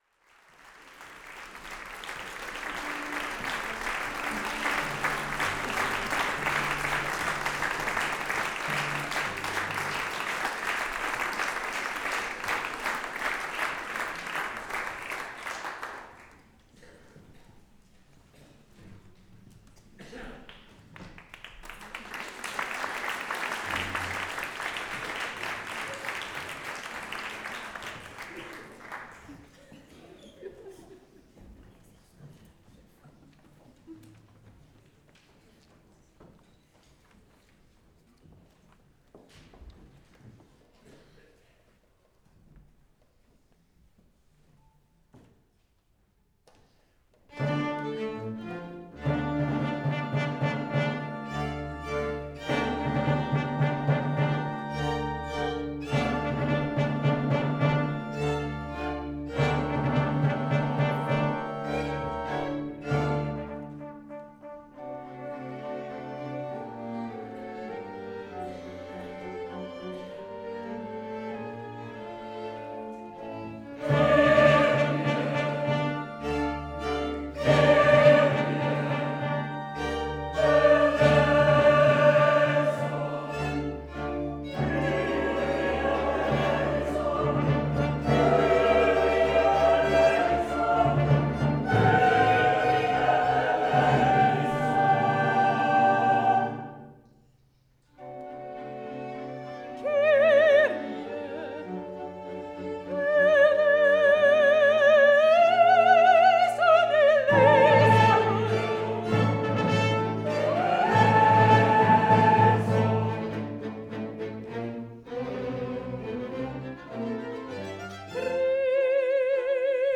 Concert de l’Avent (Kyrie)